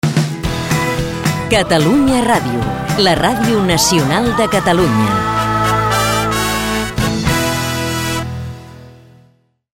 INDICATIU CATALUNYA RÀDIO